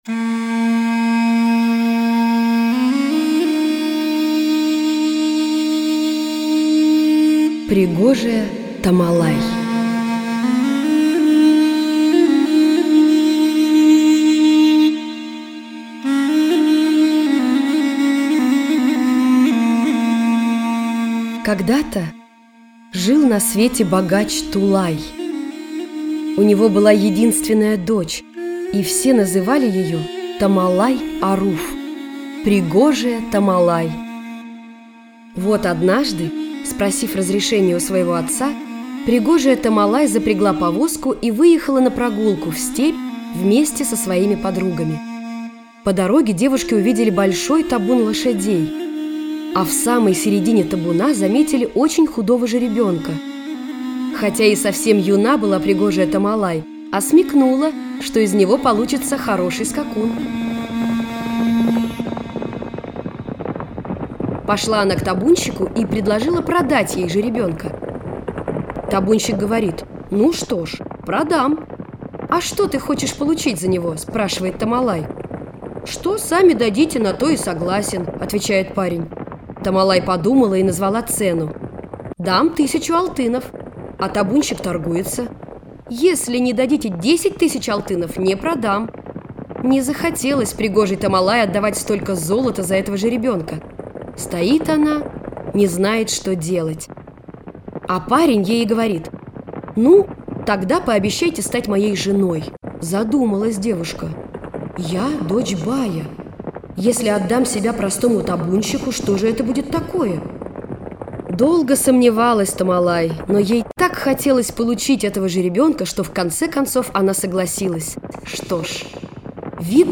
Пригожая Томалай - крымскотатарская аудиосказка - слушать онлайн